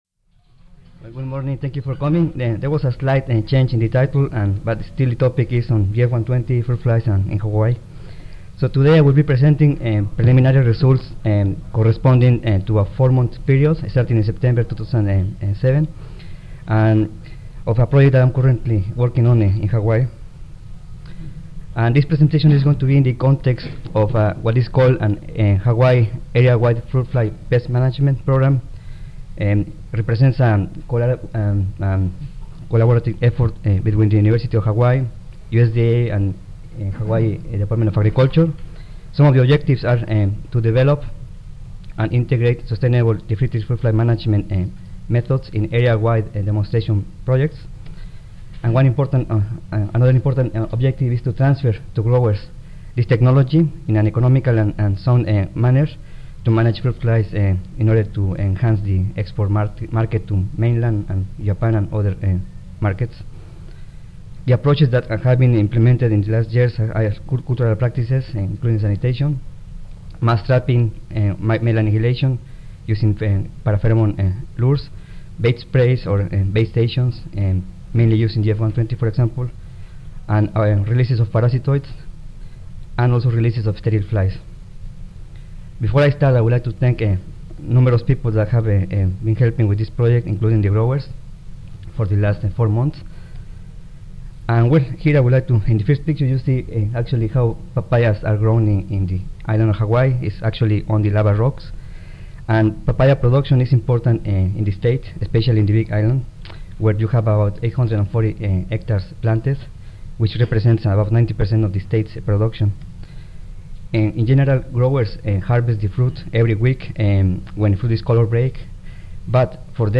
9:05 AM Recorded presentation Audio File 0864 Efficacy of GF-120 fruit fly bait sprays against Bactrocera spp. (Diptera: Tephritidae) in Hawaii